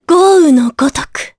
Laias-Vox_Skill4_jp.wav